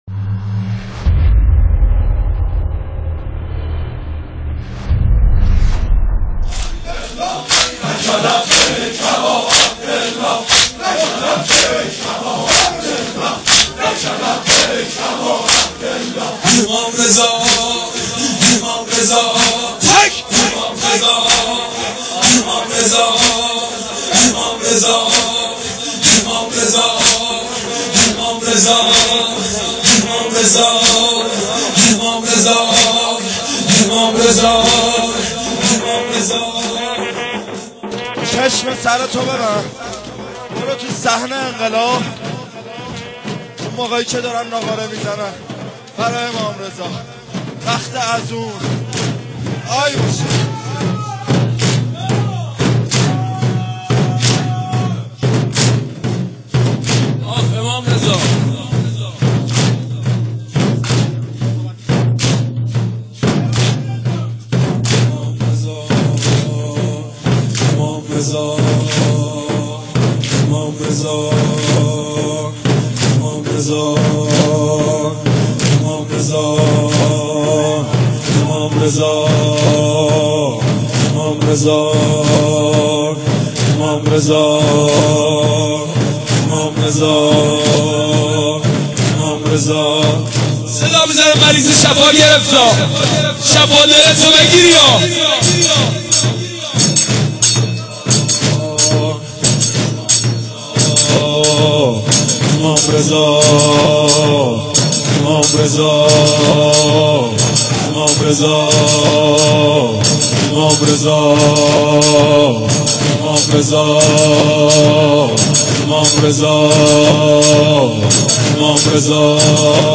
مداحی
در شب شهادت امام رضا (ع)
هیأت منتظرین حضرت مهدی (عج) - تهران